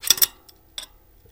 Bike Axle Nut Off